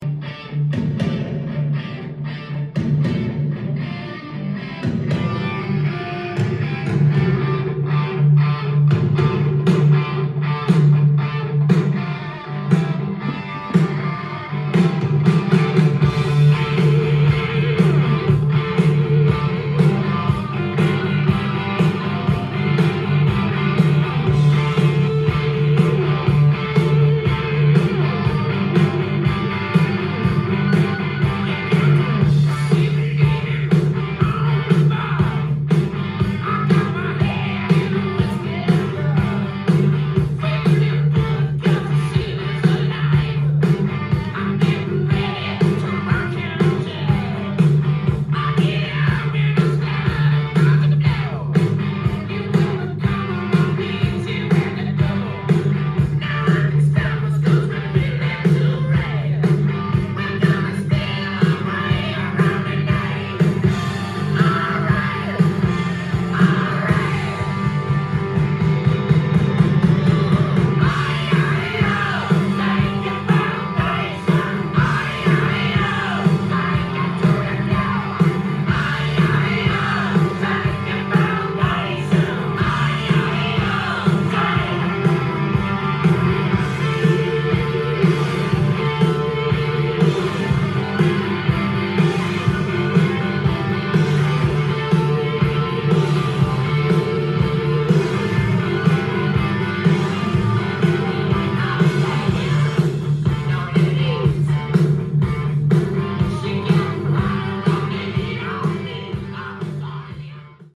ジャンル：HARD-ROCK
店頭で録音した音源の為、多少の外部音や音質の悪さはございますが、サンプルとしてご視聴ください。
セルフ・プロデュースによる剥き出しのサウンドは、まるでガレージで演奏を聴いているかのような凄まじい臨場感です。